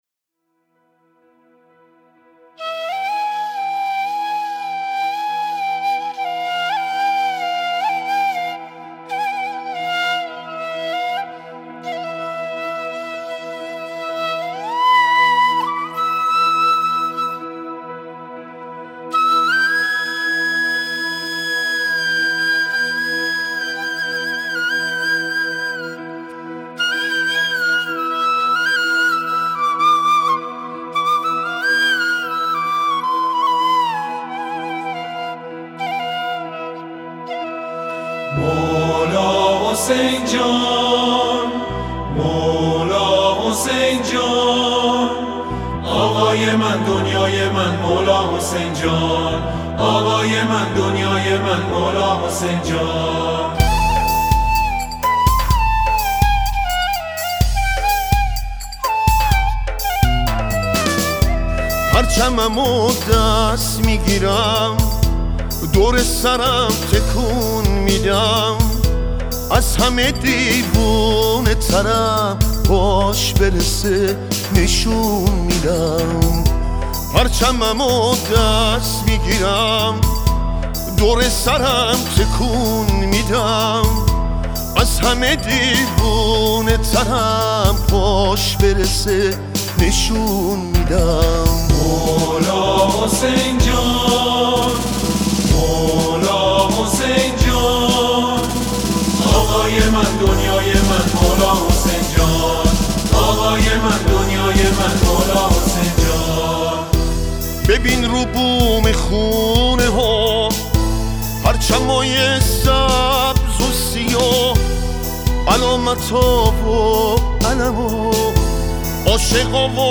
موسیقی پاپ